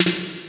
GATED S808.wav